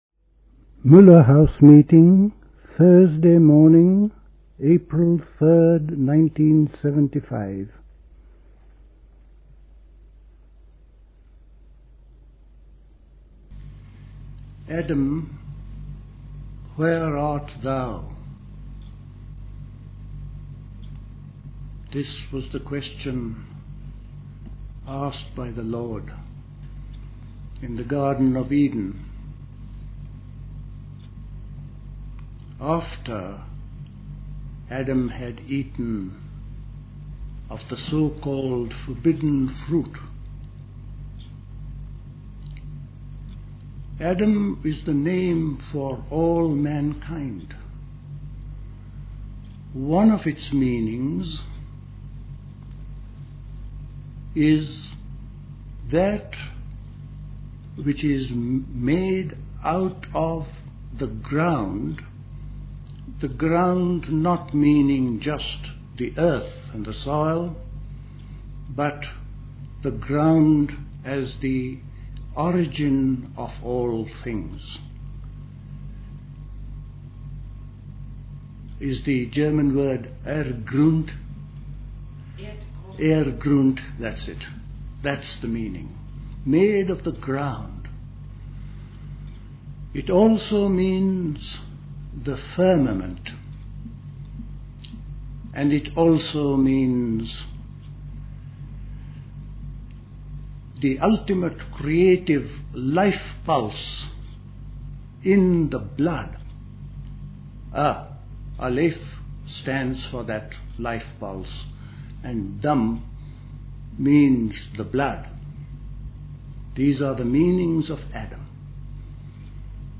A talk
Recorded at the 1975 Elmau Spring School.